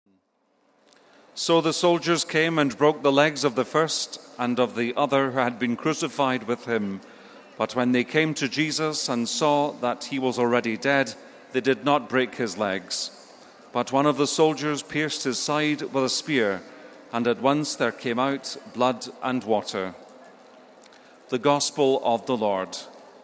29 May, 2013 - Pope Francis held his weekly general audience in the open in St. Peter’s Square in the Vatican on Wednesday. The May 29 audience began with aides reading a passage from the Gospel of St. John in several languages.